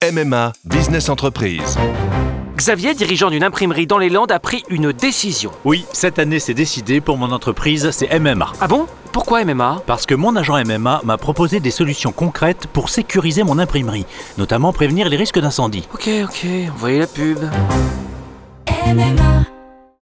LA VIE EN FLUO - Briller pour survivre - Voice over ARTE